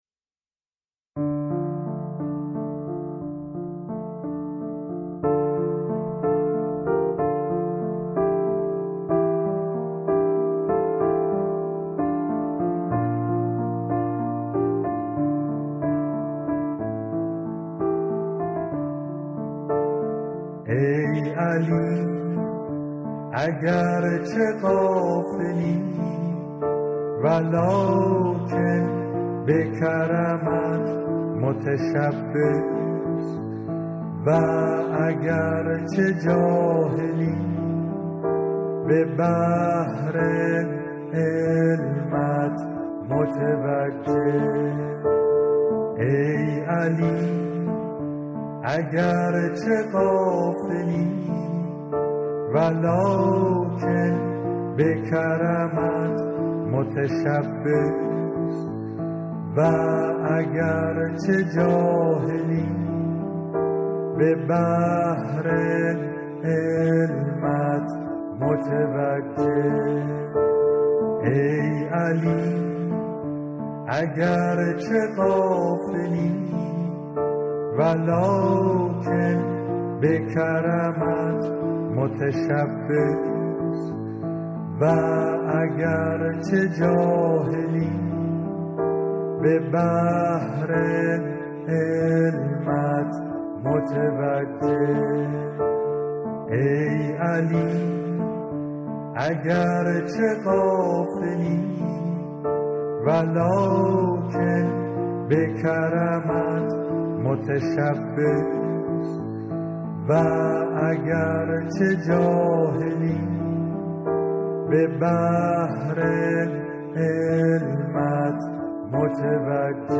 اذکار فارسی (آوازهای خوش جانان)